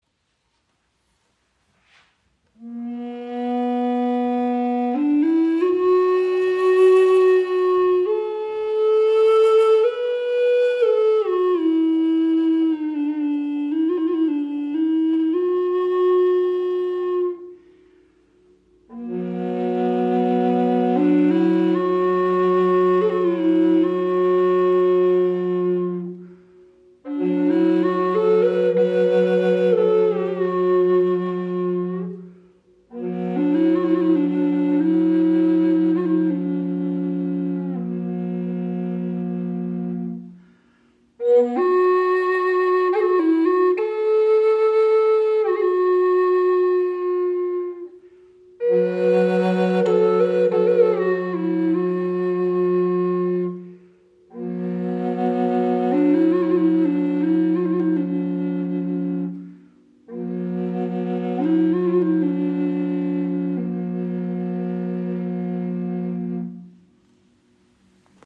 Doppelflöte Bass in F# & B - Feder im Raven-Spirit WebShop • Raven Spirit
Klangbeispiel
Sie schenkt Dir ein wundervolles Fibrato, kann als Soloinstrument gespielt werden oder als weiche Untermahlung Deiner Musik.